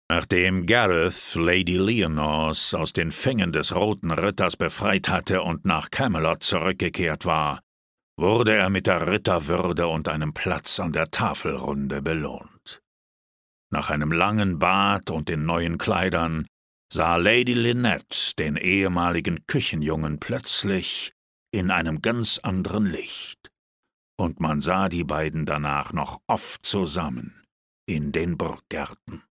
Junge Liebe - Erzähler: